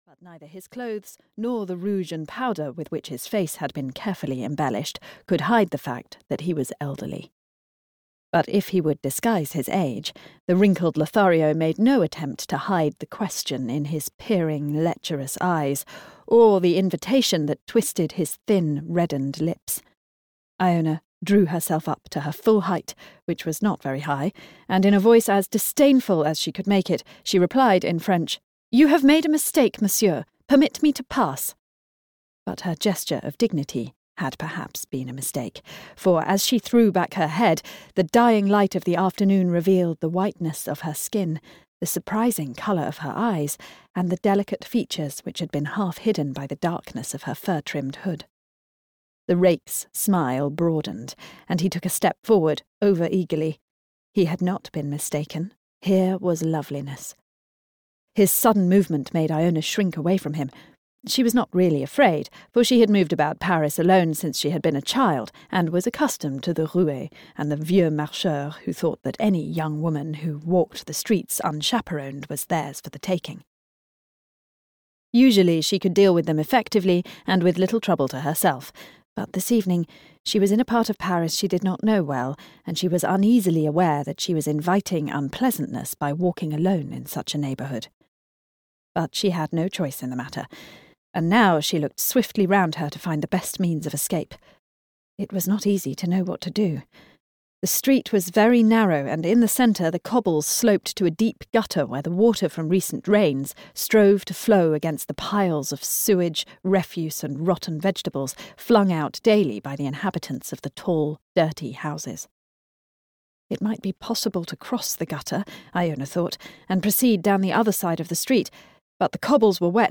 The Little Pretender (EN) audiokniha
Ukázka z knihy